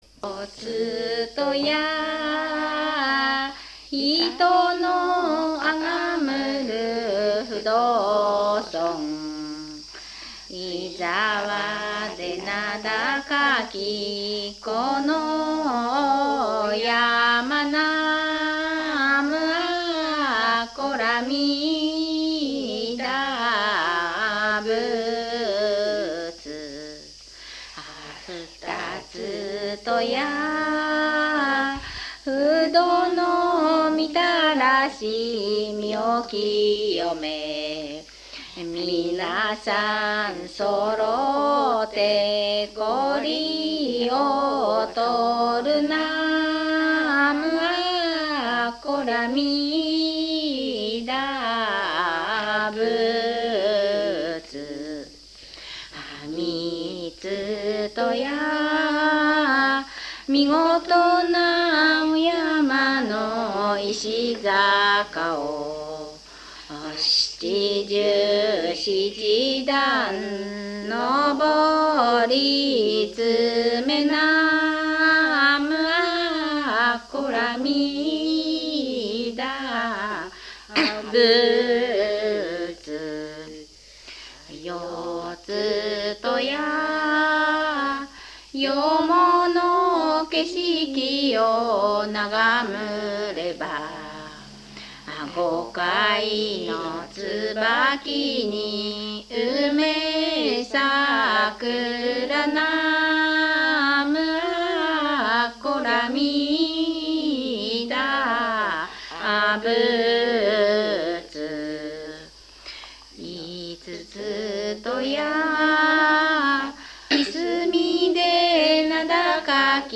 不動様のお念仏 念仏歌